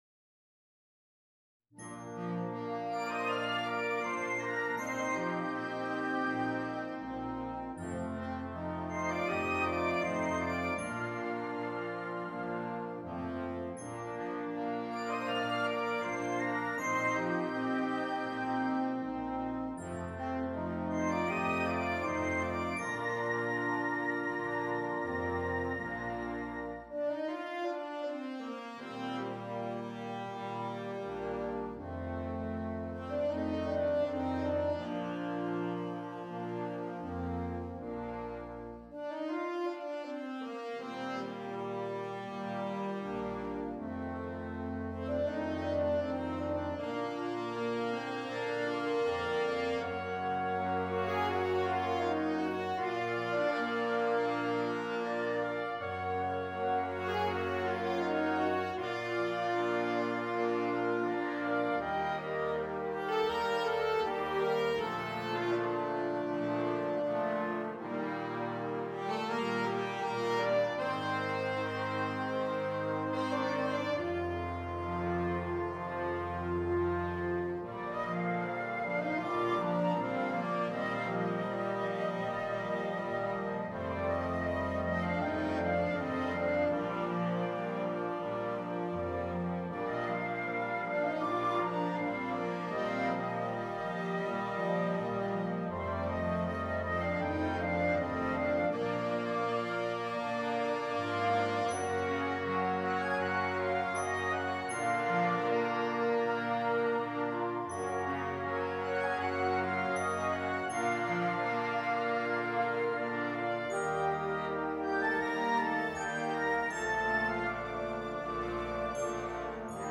for Wind Band
Solo for Soprano, Alto or Tenor Saxophone and Windband.